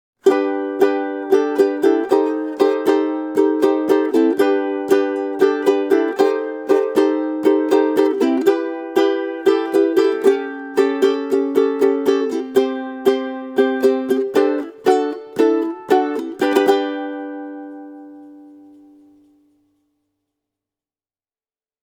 Vaikka tämä soitin ei olekaan tämän kolmikon äänekkäin ukulele, soi CK-270G erittäin kauniilla ja suloisella äänellä.